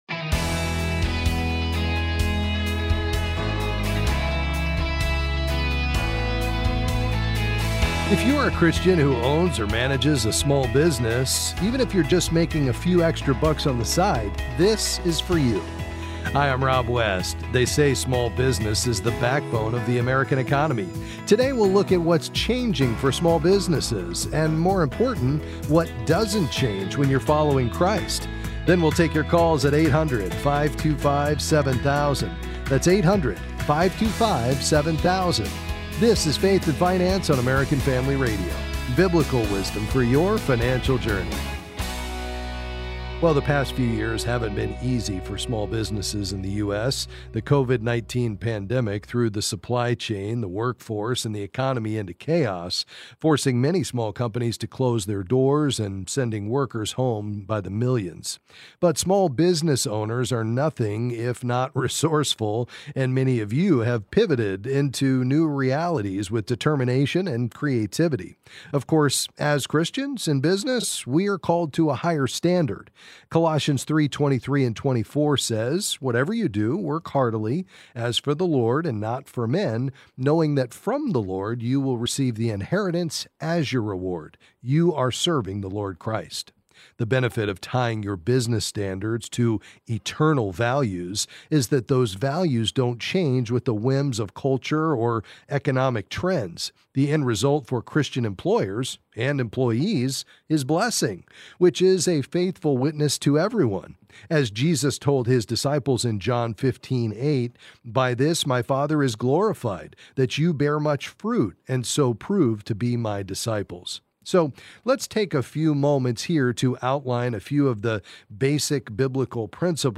Then he’ll answer your calls about various financial topics.